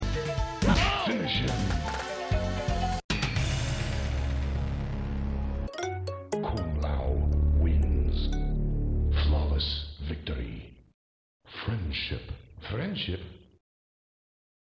Mortal Kombat II 1993 Arcade Sound Effects Free Download